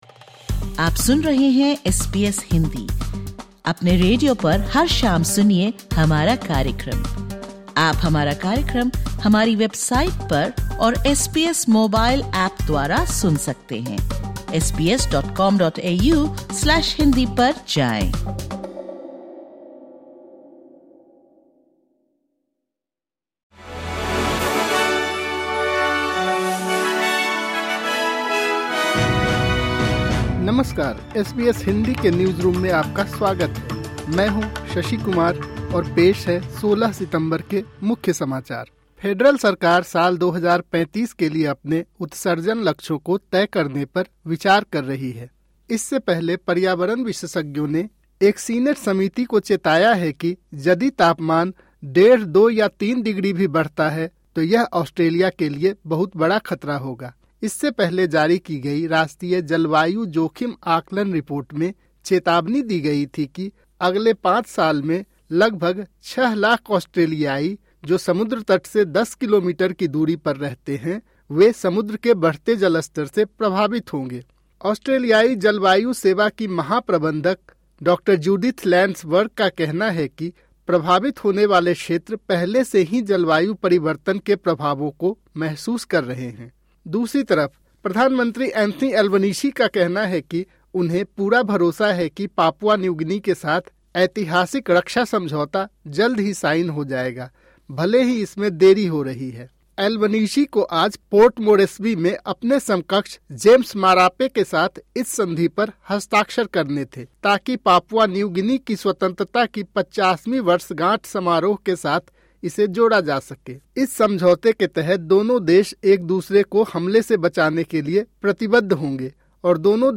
ऑस्ट्रेलिया और भारत से 13/09/2025 के प्रमुख समाचार हिंदी में सुनें।